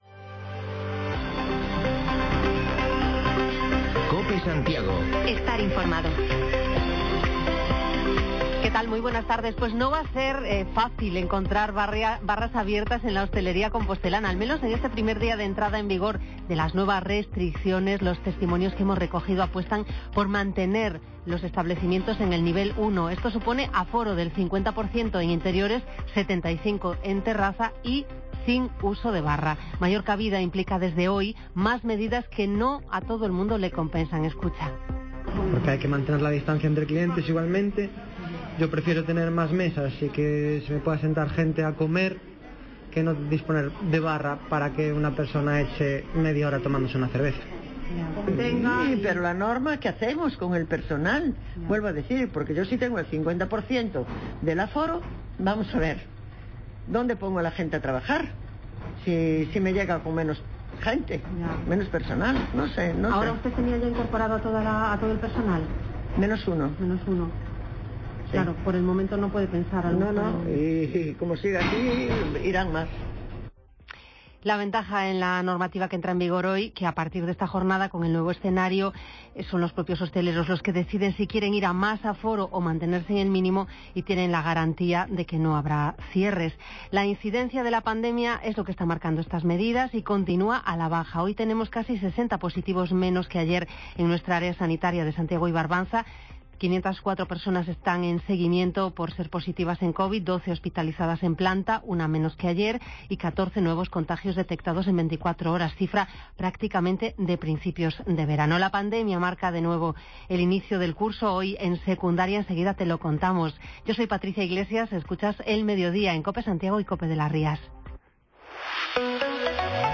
Resumen de lo más destacado que nos deja la jornada, con voces de los hosteleros ante las nuevas normas de funcionamiento, estudiantes y profes el primer día de clase en Secundaria o comerciantes de la zona histórica de Santiago.